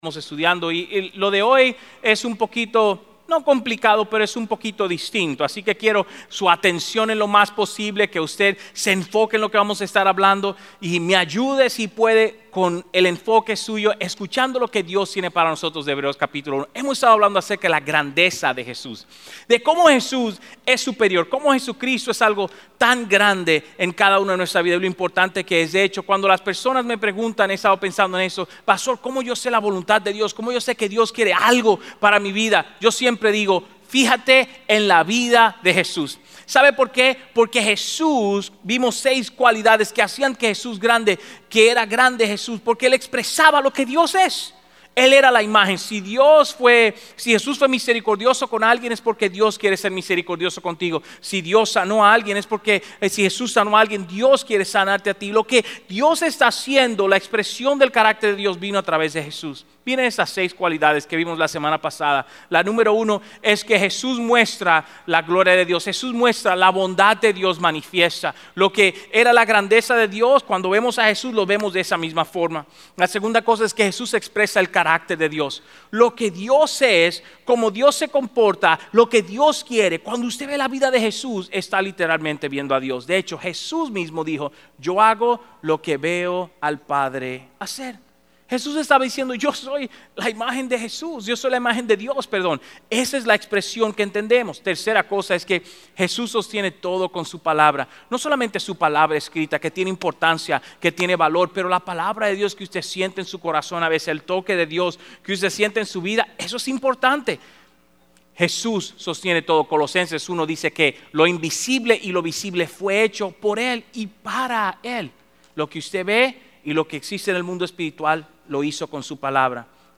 Message Series